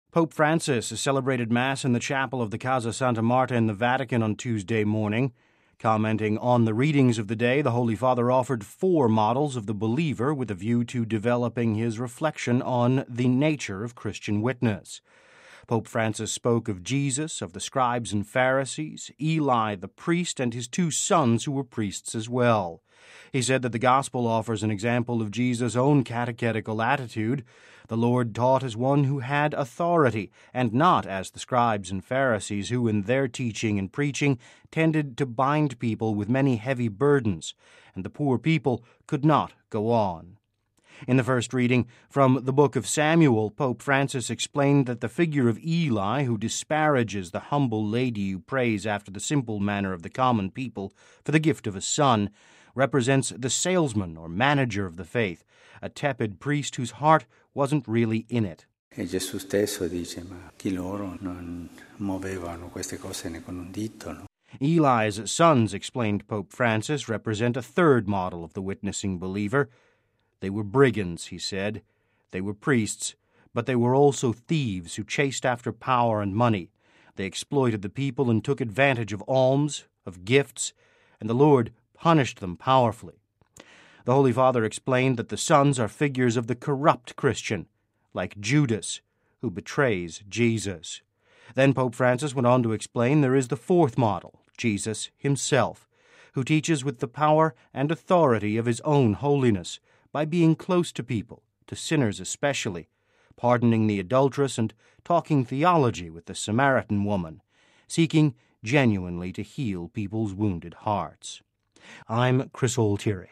(Vatican Radio) Pope Francis celebrated Mass in the chapel of the Casa Santa Marta in the Vatican on Tuesday morning. Commenting on the readings of the day, the Holy Father offered four models of the believer, with a view to developing his reflection on the nature of Christian witness.